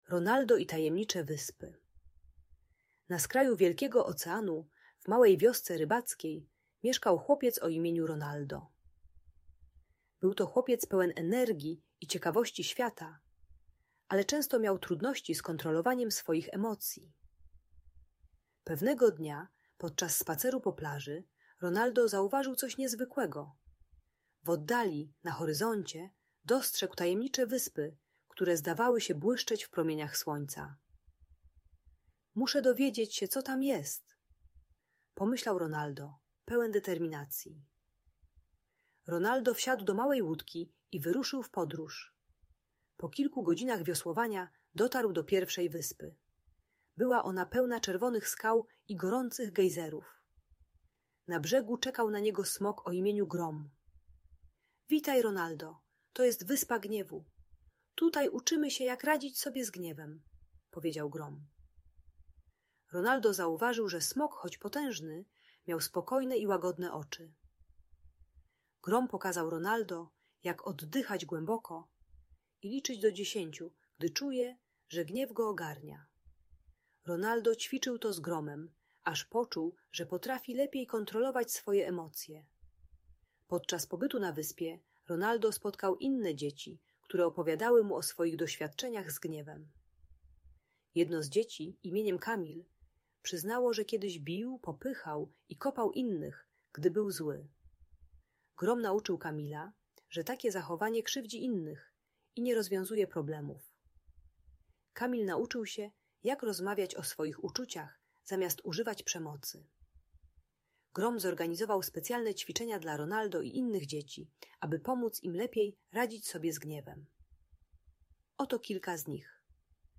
Bajka dla dziecka które bije inne dzieci i ma wybuchy złości.